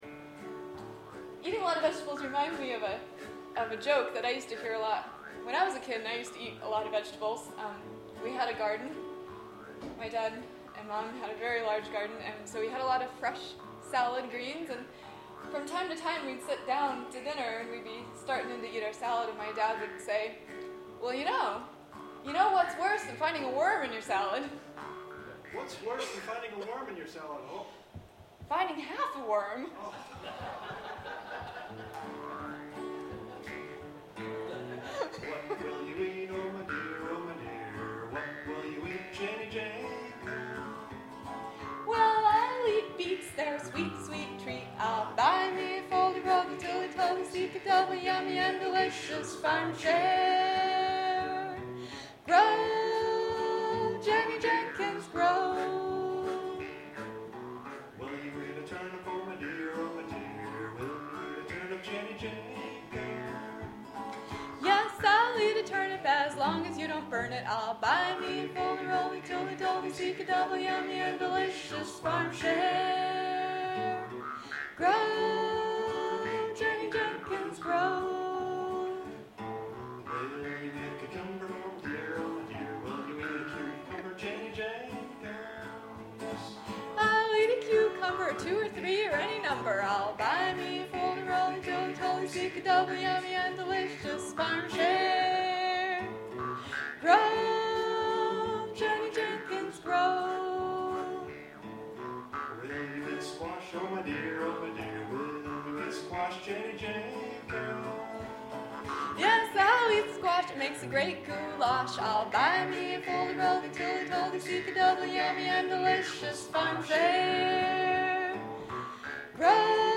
recorded live at its debut